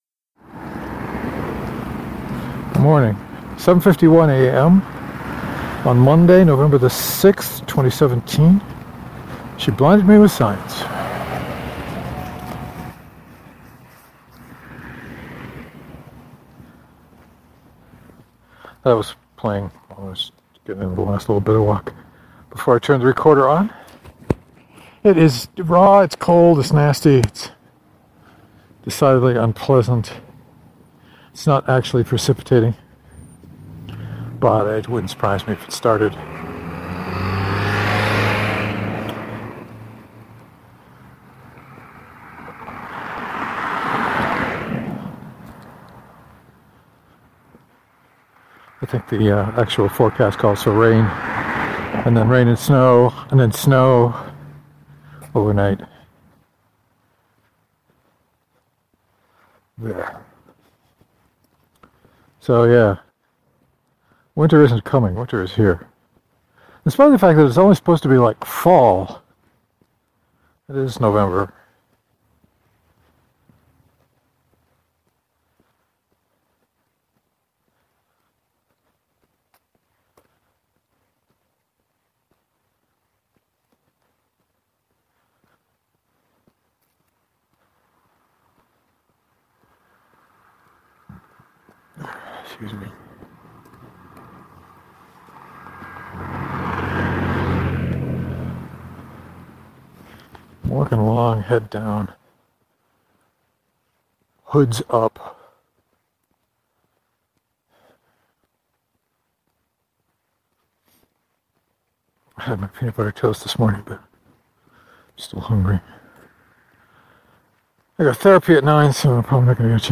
Looking at the audio file, I walked more than I talked.